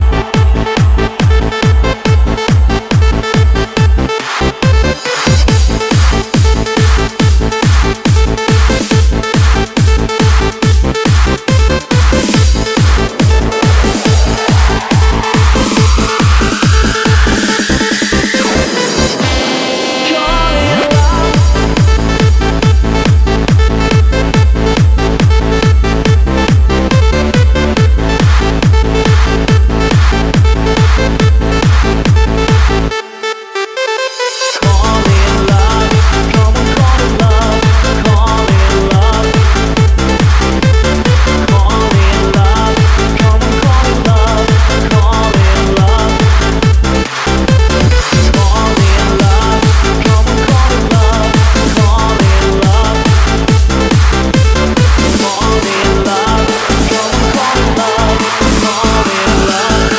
Электронная
Сборник европейских танцевальных хитов.